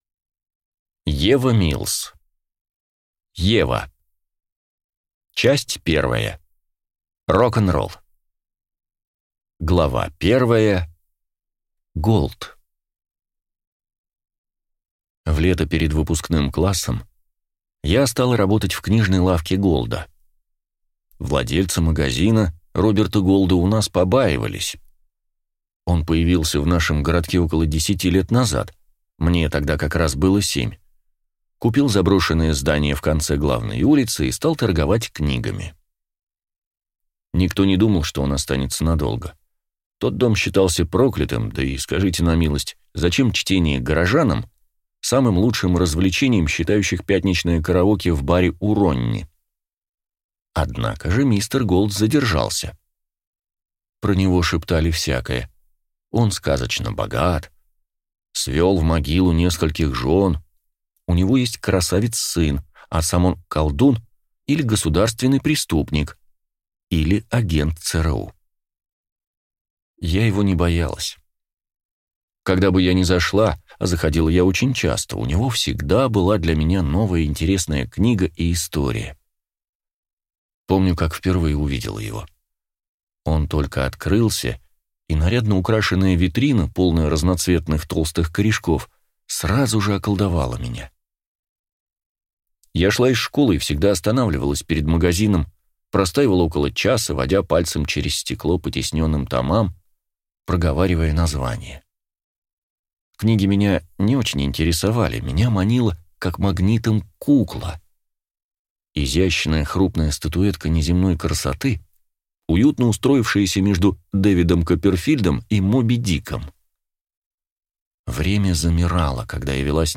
Аудиокнига Ева | Библиотека аудиокниг